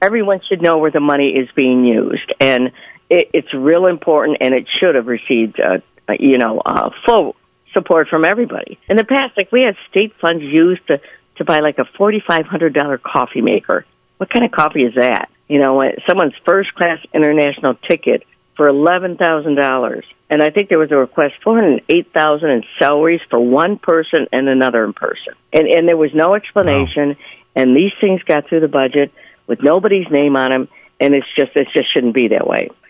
The full conversation with State Representative Schmaltz, along with other recent guest interviews, is available to listen to and download at the link below.